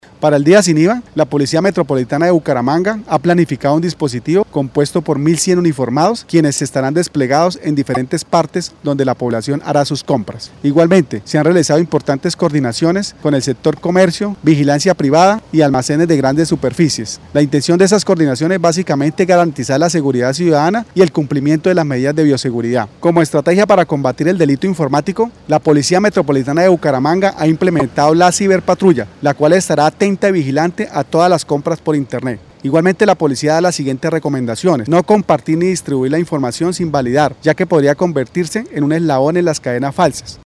Descargar audio: Luis Ernesto García, comandante de la Policía Metropolitana de Bucaramanga, Mebuc
Luis-Ernesto-Garcia-comandante-de-la-Policia-dIA-SIN-iva.mp3